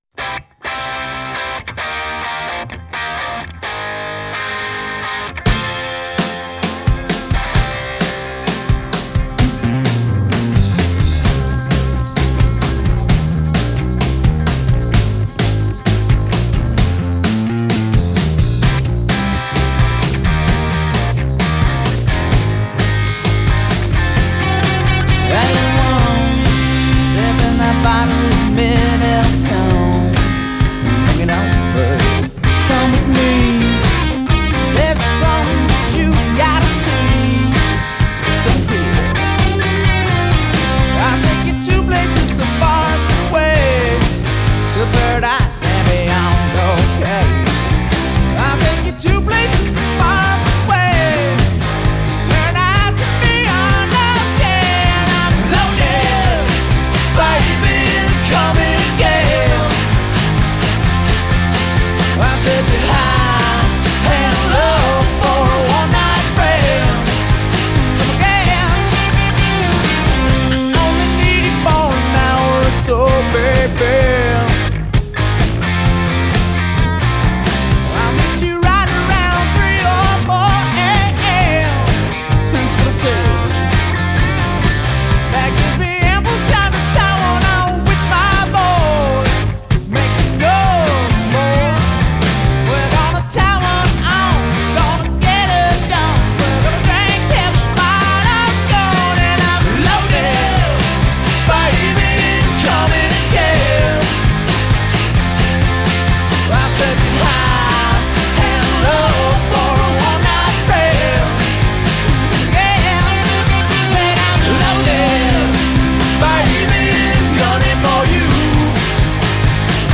American rock band